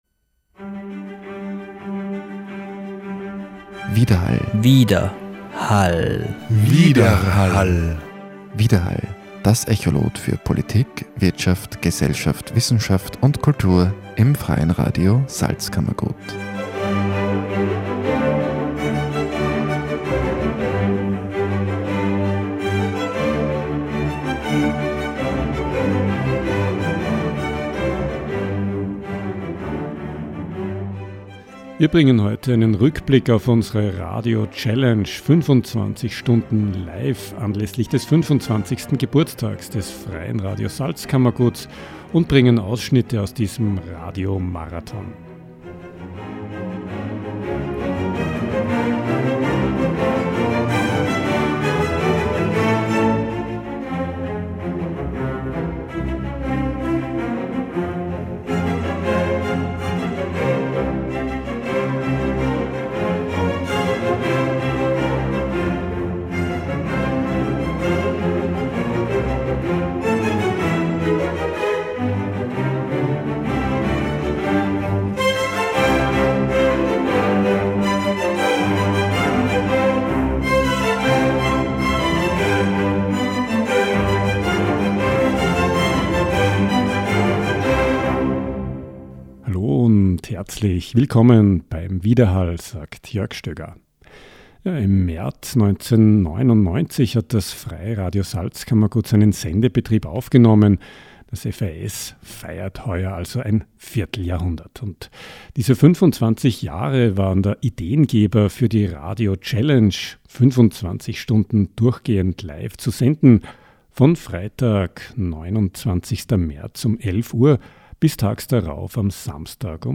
Radiomachende erzählten etwa über die Beginnzeit des FRS und über ihre Motivation, Radio zu gestalten. Wir bringen einen Rückblick auf diesen Radiomarathon, akustische Splitter dieser 25 Stunden – ganz und gar nicht vollständig.